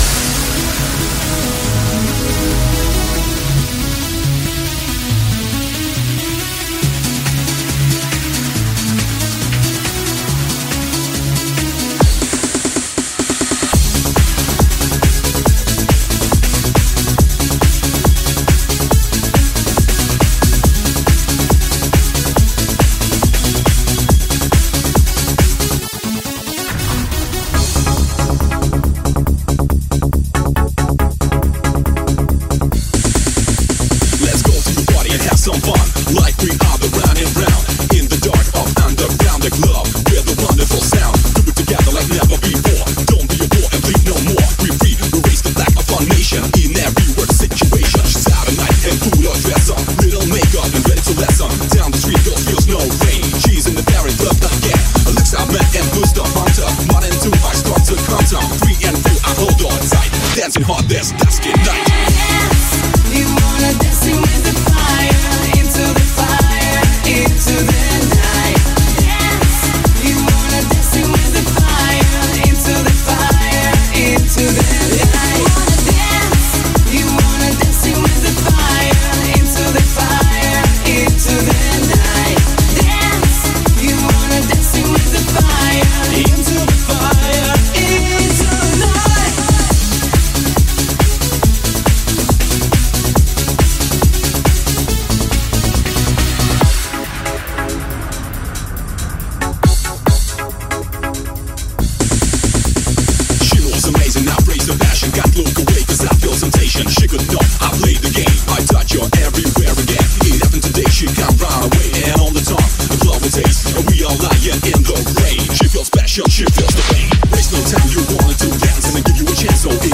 Лучшие танцевальные 90___Vol 11__
Luchshie-tancevaljqnye-90___Vol-11__.mp3